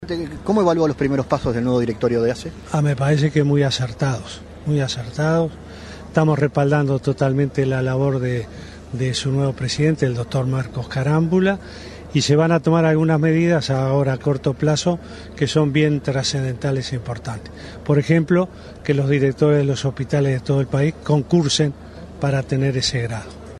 El presidente Tabaré Vázquez respaldó la labor del nuevo presidente de la Administración de Servicios de Salud del Estado (ASSE), Marcos Carámbula, y dijo que es importante la medida adoptada por el nuevo directorio de que los directores de hospitales concursen para acceder a esos cargos. Vázquez dijo a la prensa, previo al inicio del Consejo de Ministros abierto de San José, que esa medida es muy acertada.